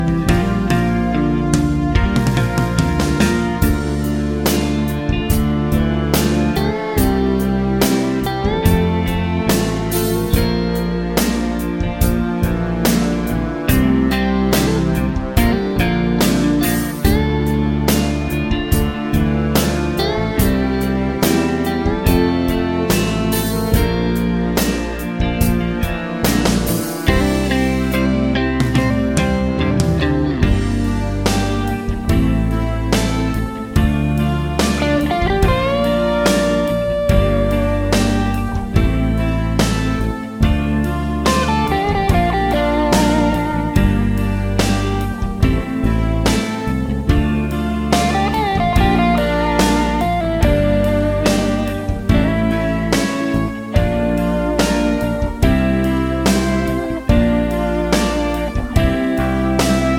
no Backing Vocals Soft Rock 3:42 Buy £1.50